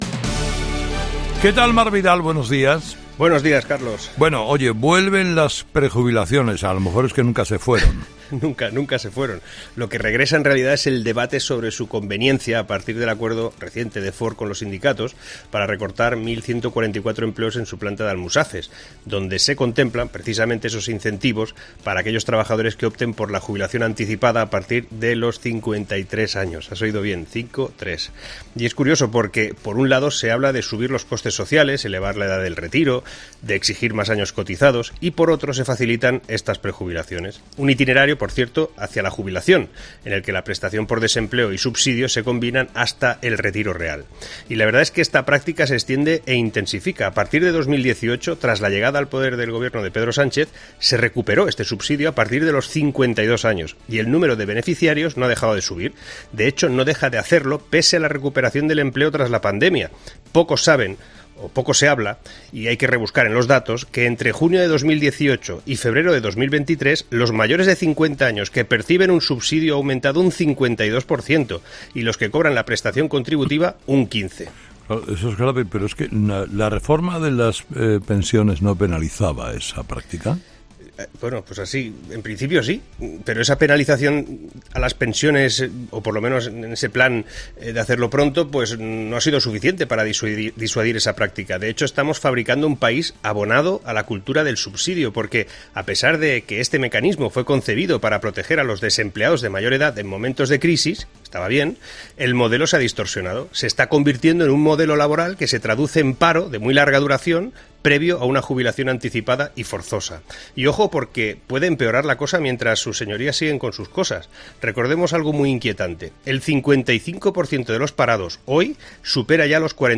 El analista económico ha abierto el debate sobre el acuerdo de Ford en su fábrica en Almussafes con el ingreso de ERE a 1.144 trabajadores a través de prejubilaciones
Carlos Herrera ha lanzado una cuestión a Vidal: “¿La reforma de las pensiones no penalizaba esta práctica?” a lo que el analista económico ha respondido afirmando que “en principio sí, pero esa penalización a las pensiones, o por lo menos en ese plan de hacerlo pronto, no ha sido suficiente para disuadir esa práctica".